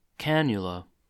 A cannula (/ˈkænjʊlə/
En-us-cannula.oga.mp3